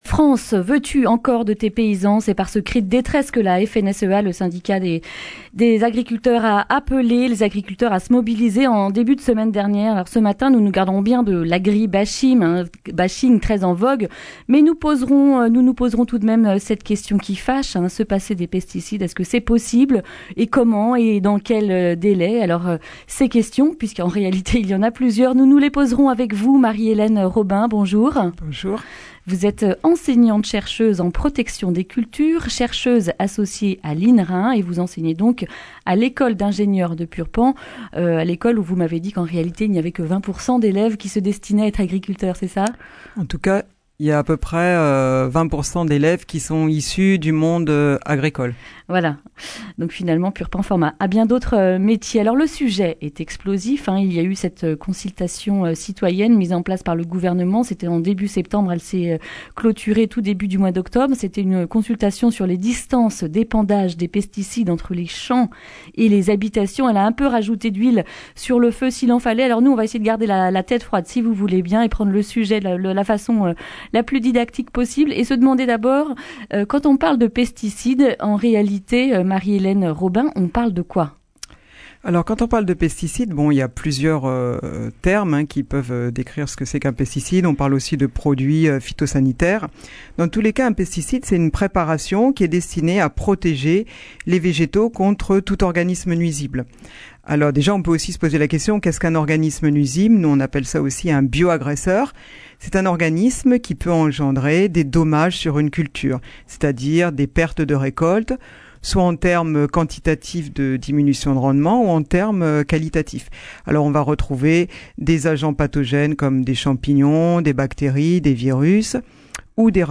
Accueil \ Emissions \ Information \ Régionale \ Le grand entretien \ Pesticides : « Prenons le temps d’en sortir !